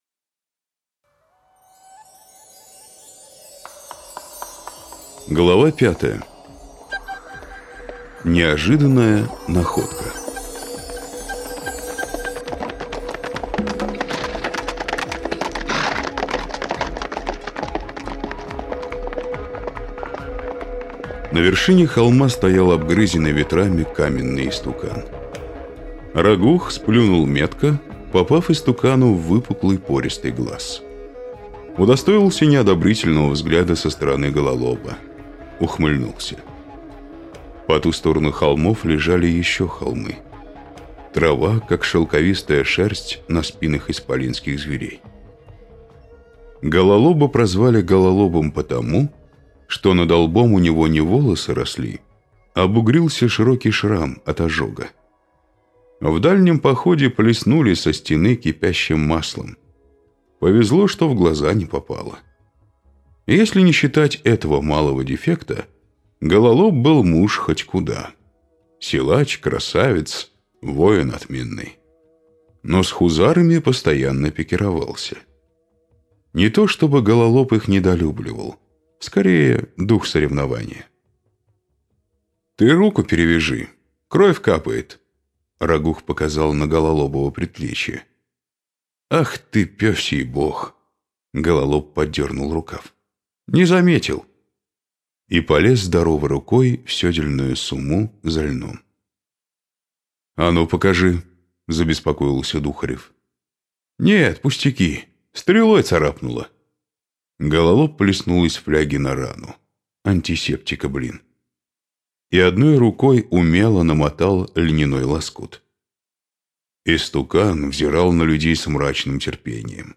Аудиокнига Место для битвы - купить, скачать и слушать онлайн | КнигоПоиск